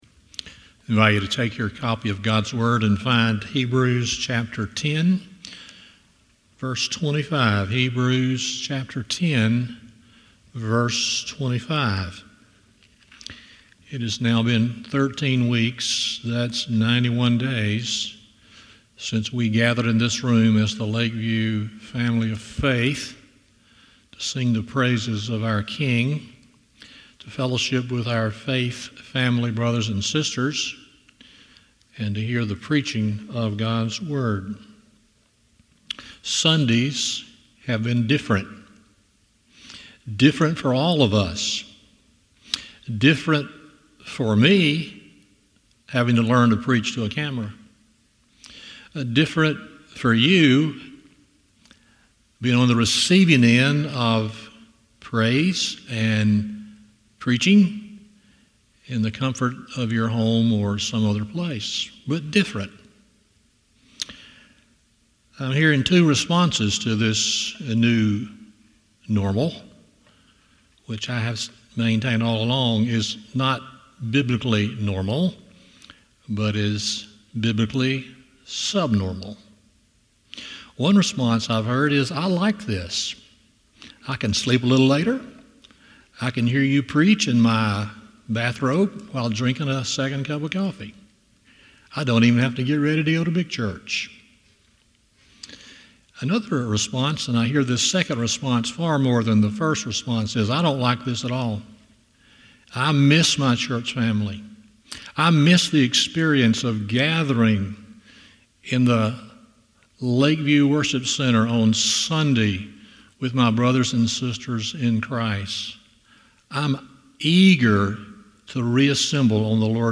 Hebrews 10:25 Service Type: Sunday Morning 1.